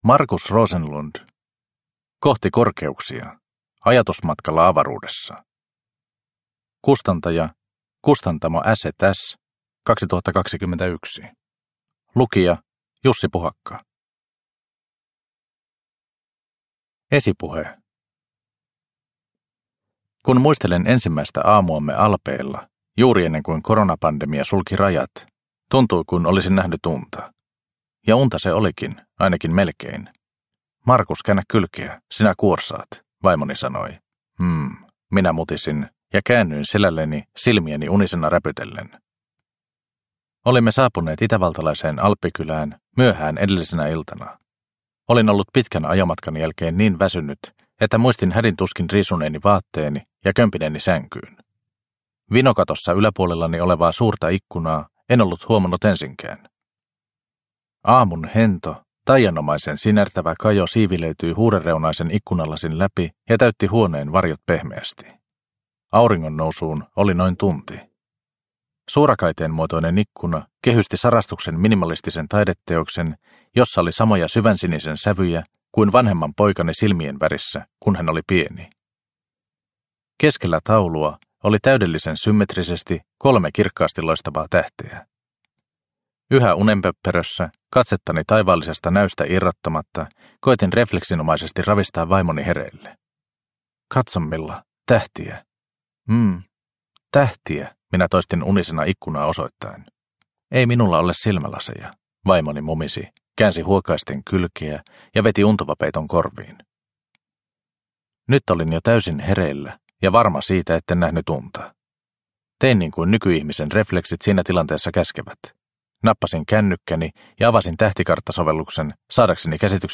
Kohti korkeuksia – Ljudbok – Laddas ner